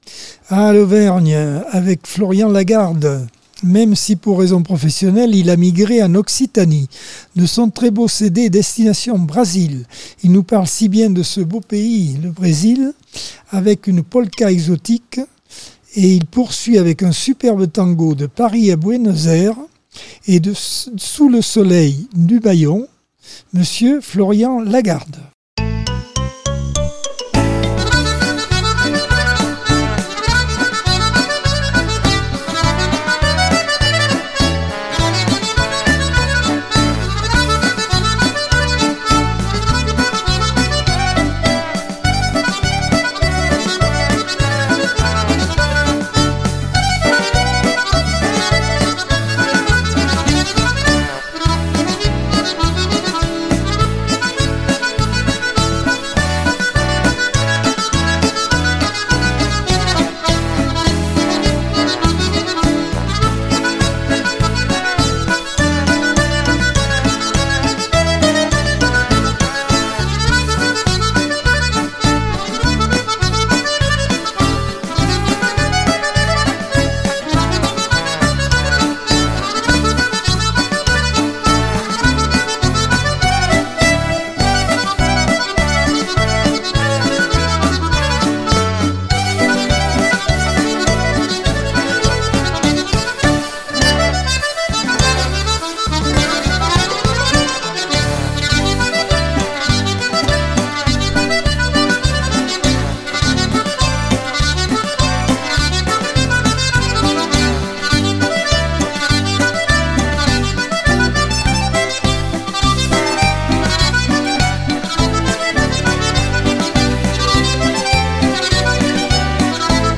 Accordeon 2024 sem 33 bloc 4 - Radio ACX